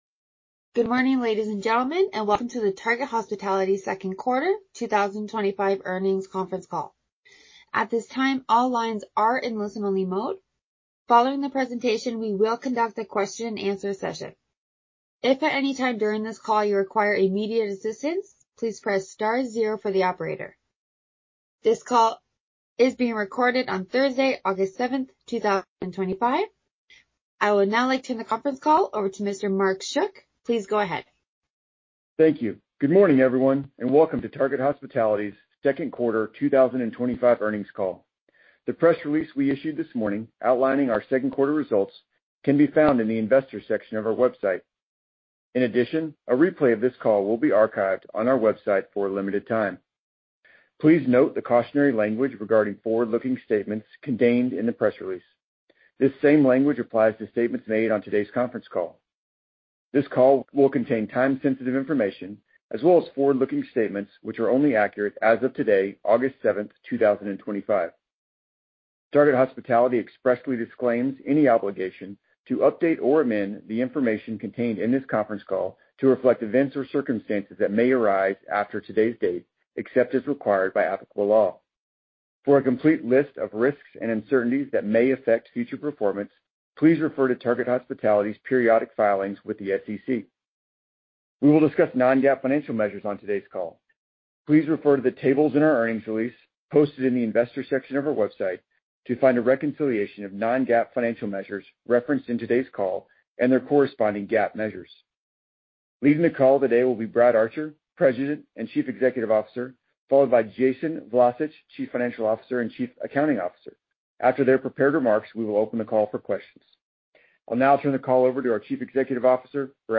Second Quarter 2025 Earnings Call
A replay of the conference call can be accessed here: Second Quarter 2025 Earnings Conference Call MP3